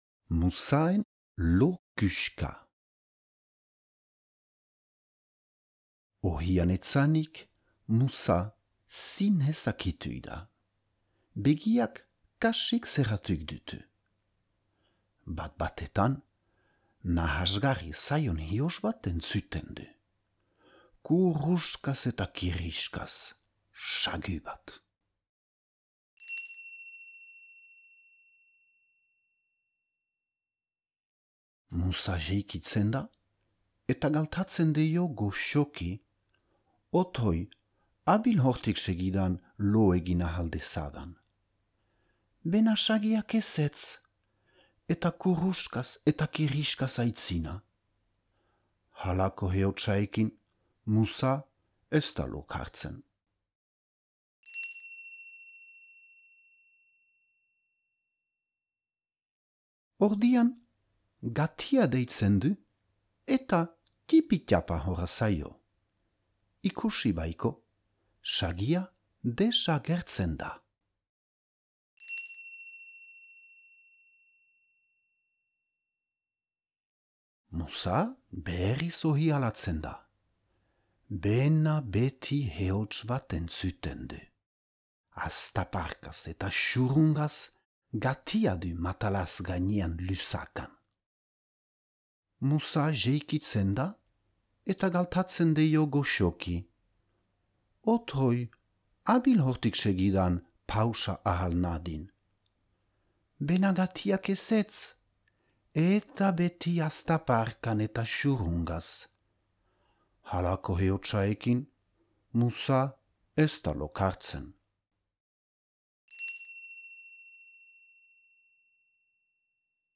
Muzaren lo küxka - zubereraz - ipuina entzungai